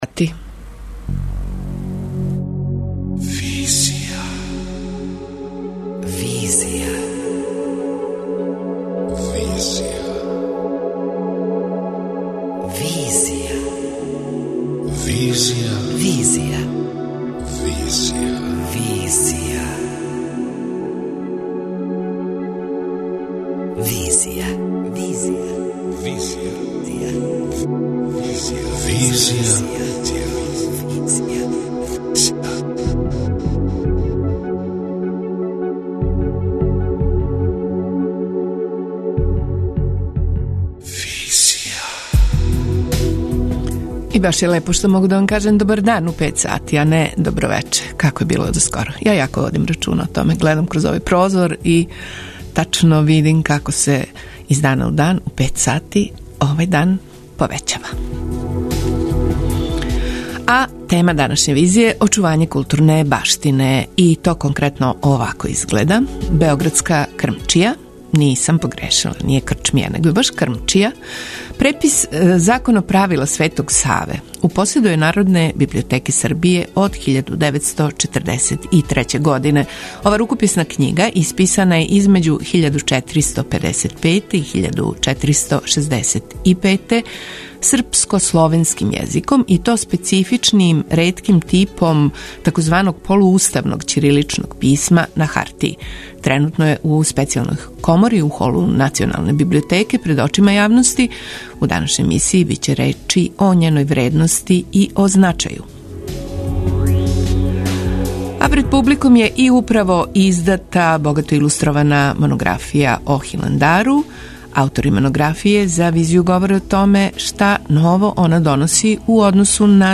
Аутори монографије за „Визију" говоре о томе шта ново она доноси у односу на досадашња издања о Хиландару.